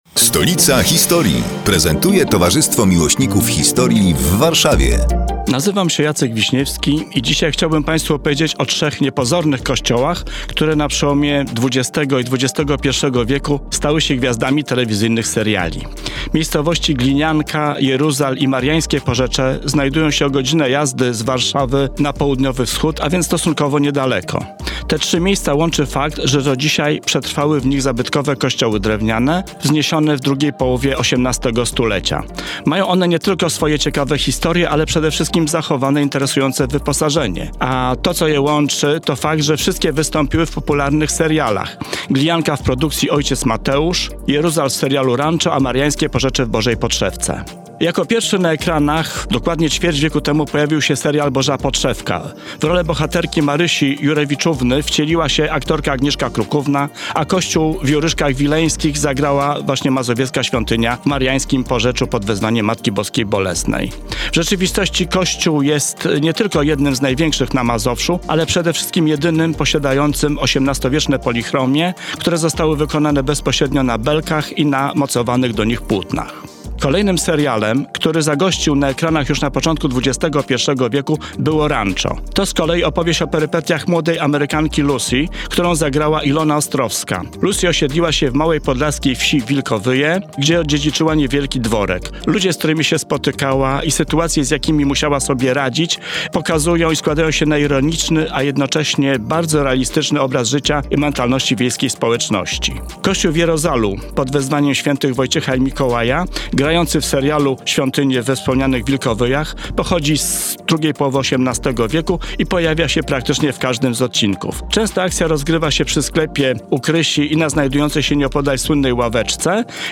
Przedstawiają członkowie Towarzystwa Miłośników Historii w Warszawie, które są już od pół roku emitowane w każdą sobotę o 15:15, w nieco skróconej wersji, w Radiu Kolor.